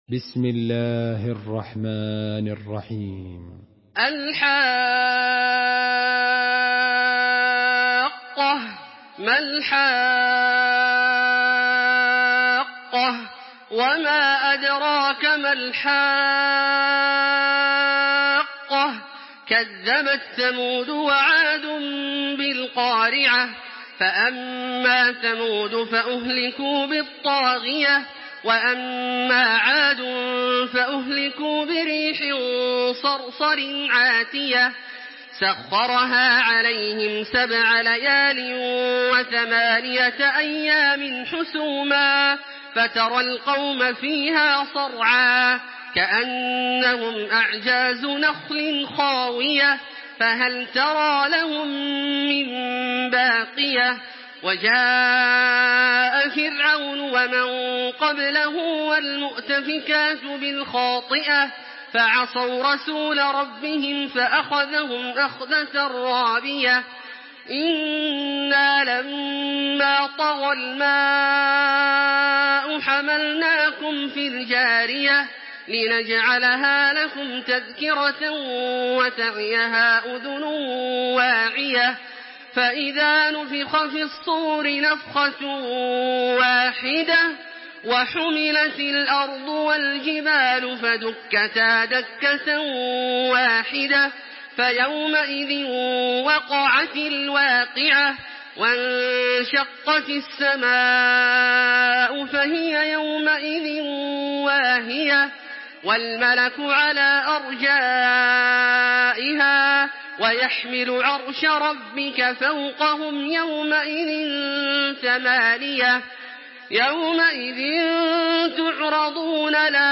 Surah Hakka MP3 by Makkah Taraweeh 1426 in Hafs An Asim narration.
Murattal